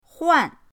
huan4.mp3